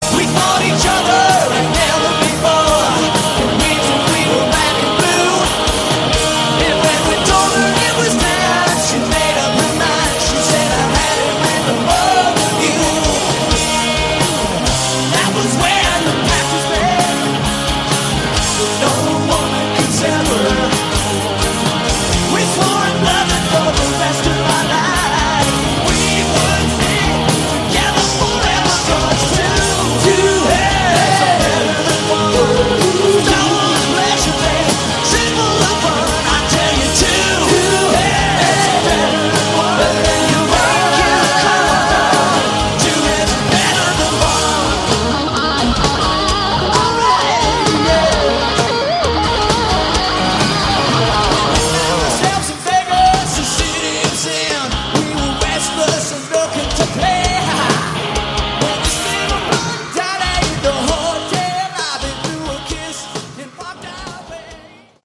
Category: AOR
lead vocals, guitar
lead vocals, bass
vocals, keyboards
drums
live